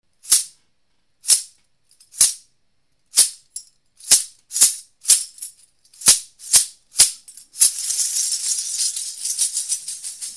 Branch Rattle with metal jingles. 15 inches long.
Branch-sistrum-metal.mp3